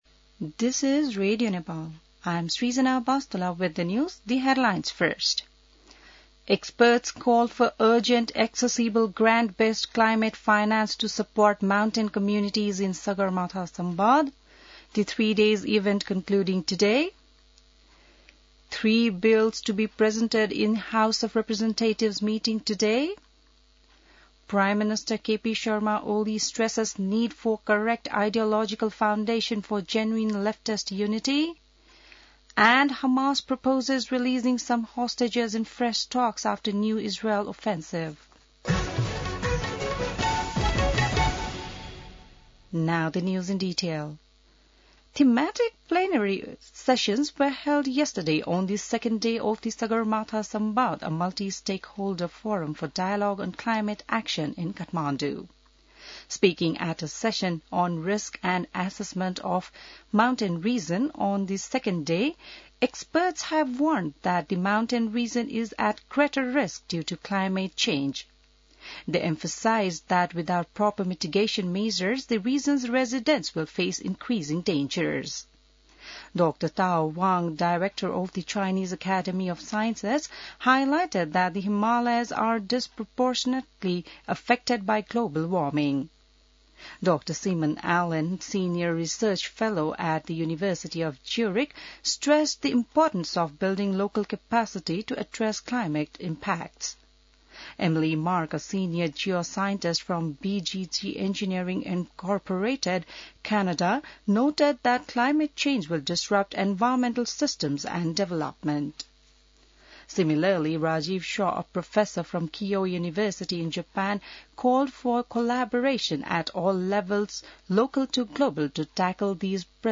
An online outlet of Nepal's national radio broadcaster
बिहान ८ बजेको अङ्ग्रेजी समाचार : ४ जेठ , २०८२